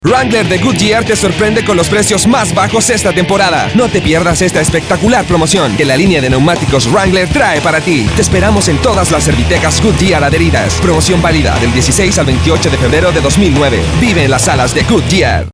Dialekt 1: chilenisch